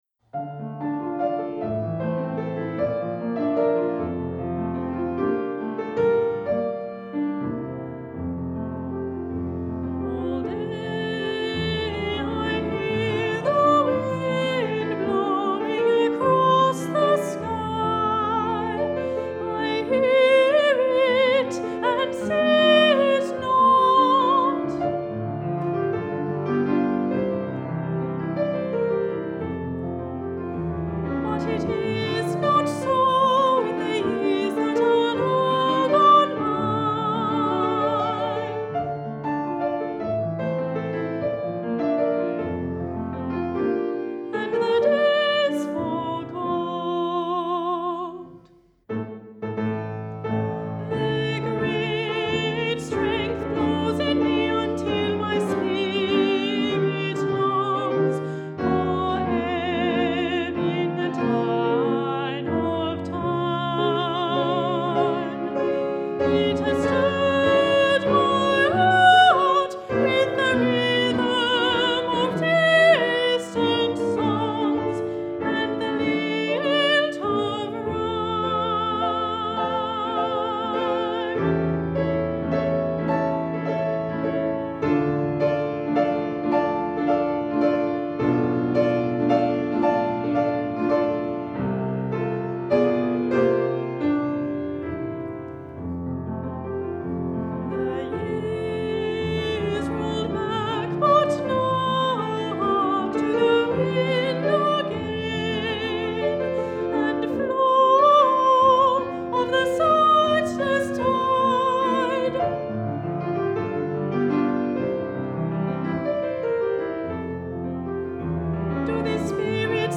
Mezzo-Soprano.
Piano.